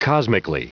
Prononciation du mot cosmically en anglais (fichier audio)
Prononciation du mot : cosmically